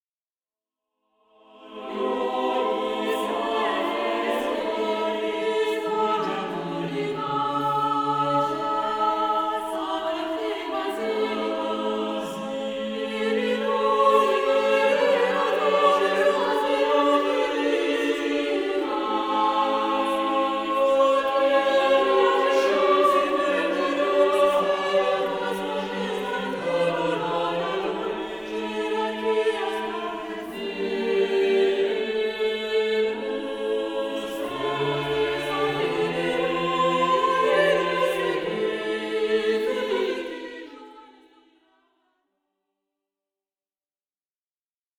Plain-chant et polyphonies du 14e siècle
Motet